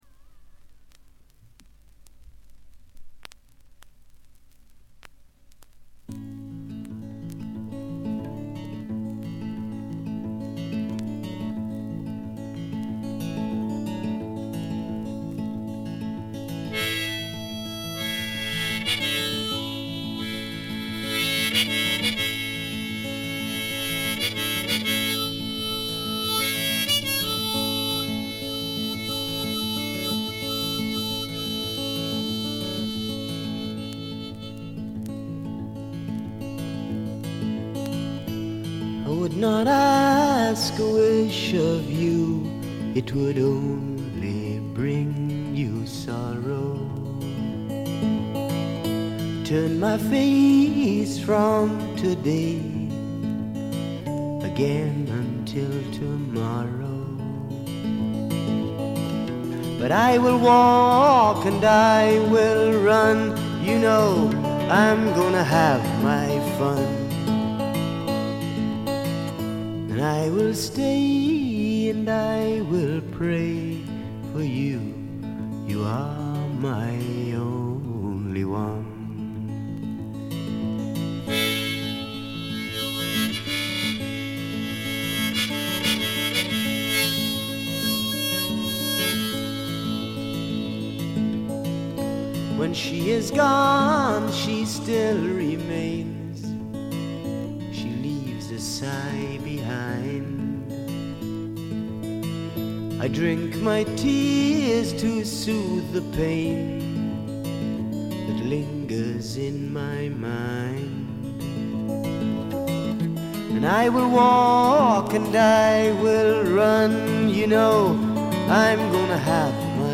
A2とB2でプツ音1回づつ。
英国産「木漏れ日フォーク」には程遠い、荒涼とはしているものの気品のある風景画のような楽曲群です。
試聴曲は現品からの取り込み音源です。